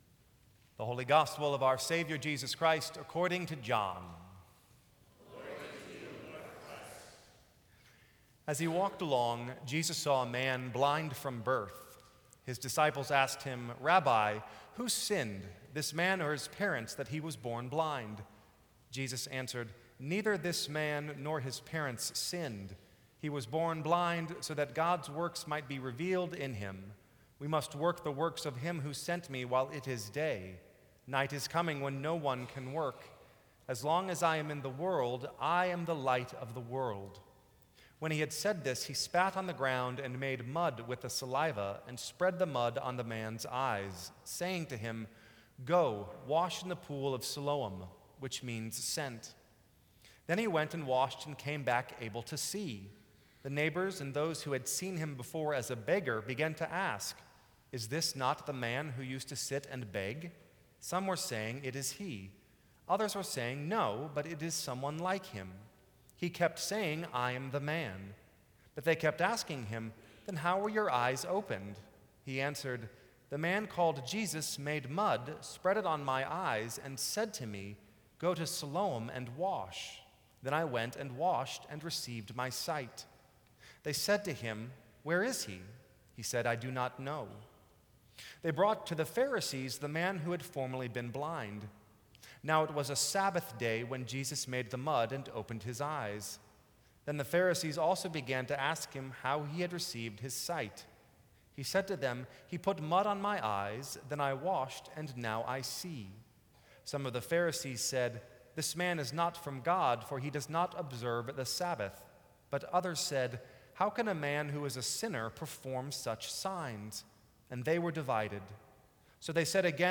Sermons from St. Cross Episcopal Church March 30, 2014.